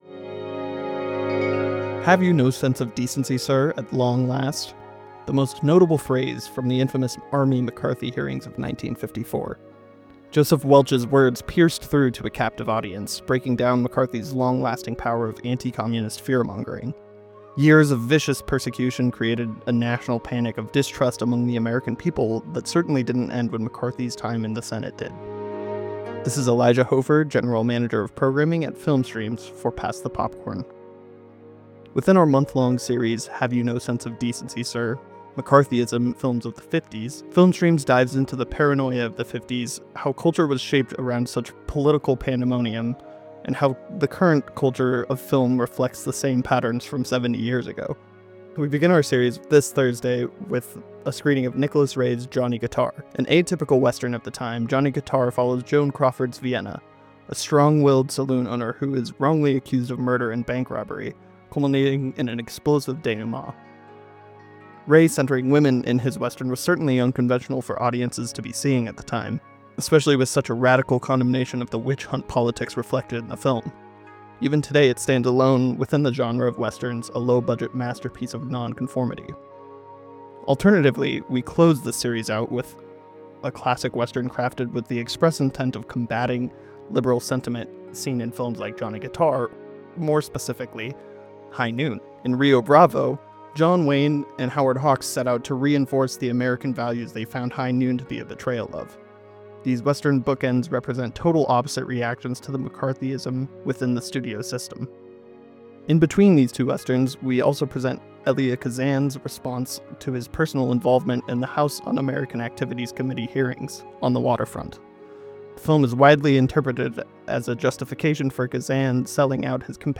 Logo for Pass the Popcorn, KVNO’s film segment, featuring Film Streams.